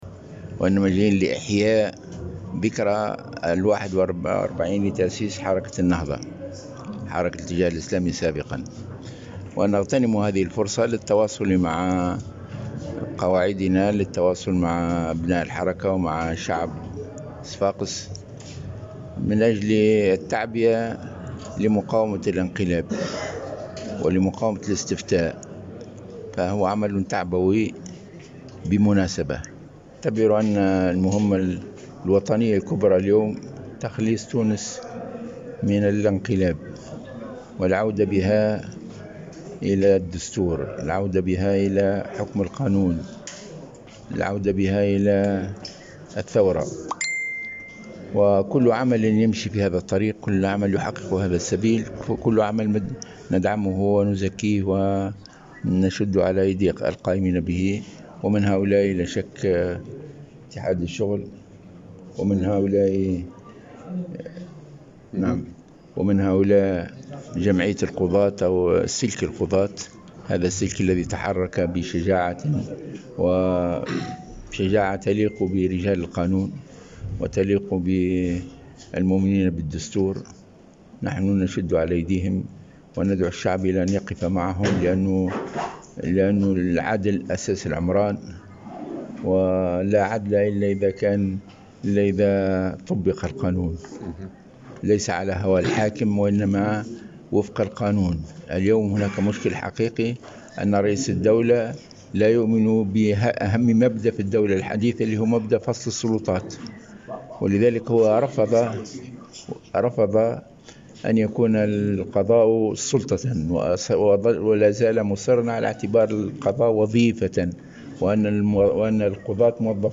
وأثنى الغنوشي في تصريح لمراسل الجوهرة اف ام، على هامش اجتماع شعبي لحركة النهضة بمناسبة الذكرى 41 لتأسيس الحركة، على كل من يساهم في مسار استرجاع مسار الثورة، وعلى رأسهم الاتحاد العام اللتونسي للشغل وسلك القضاة، داعيا الشعب إلى الوقوف مع القضاة في ظل عدم إيمان رئيس الدولة بمبدأ فصل السلطات واستقلال القضاء، بحسب تعبيره.